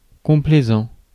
Ääntäminen
Synonyymit civil affable Ääntäminen France: IPA: [kɔ̃.plɛ.zɑ̃] Haettu sana löytyi näillä lähdekielillä: ranska Käännös Ääninäyte Adjektiivit 1. complaisant 2. obliging 3. eager to please 4. complacent US Suku: m .